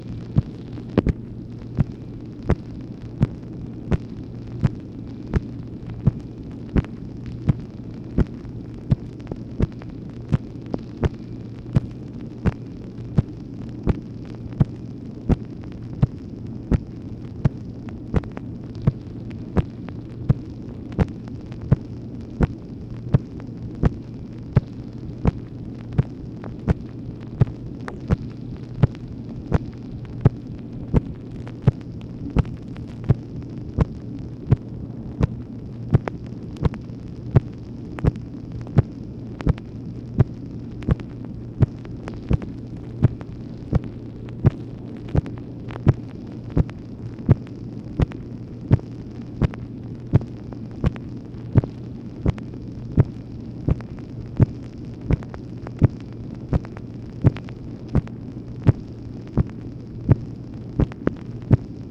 MACHINE NOISE, June 9, 1965
Secret White House Tapes